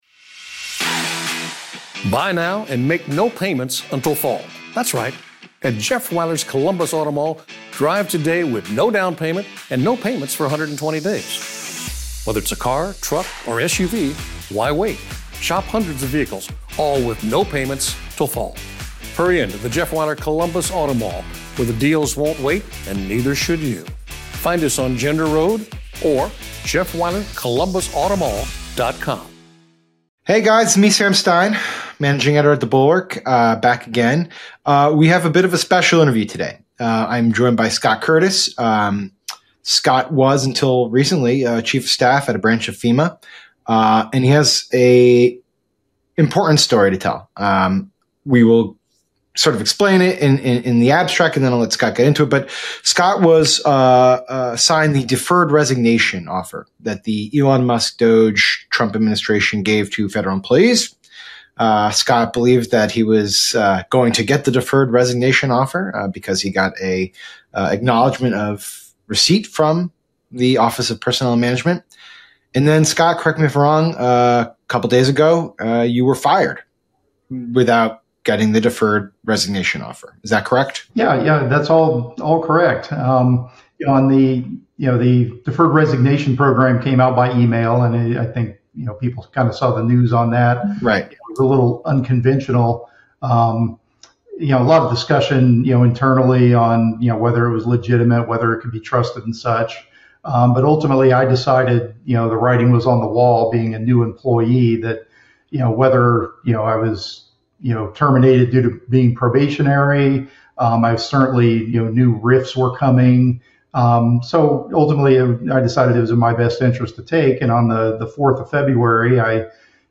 UPDATE: On Friday, after this interview was posted, he was rehired.